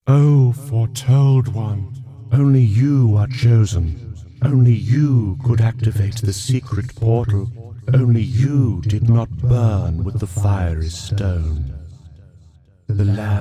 stones_one.ogg